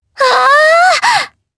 Sonia-Vox_Happy4_jp_b.wav